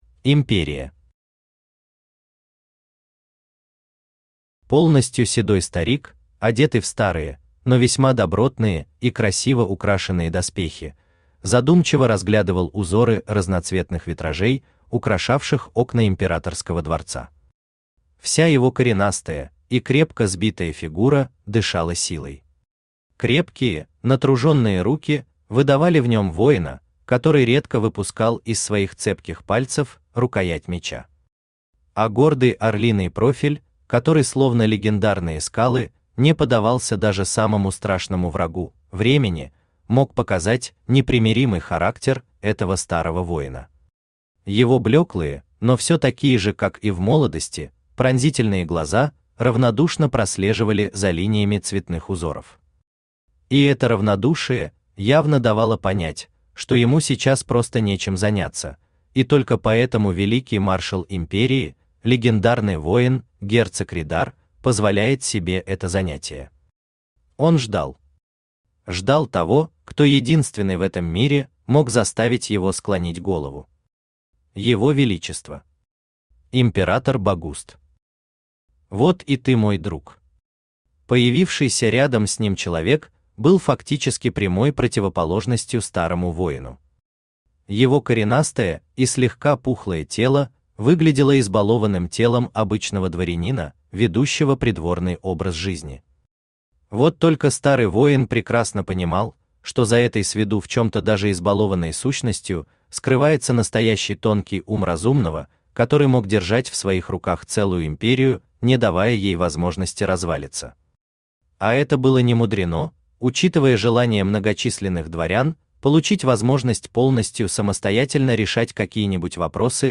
Аудиокнига Лесовик. Часть 2. Кромешные дебри учёбы | Библиотека аудиокниг
Кромешные дебри учёбы Автор Хайдарали Усманов Читает аудиокнигу Авточтец ЛитРес.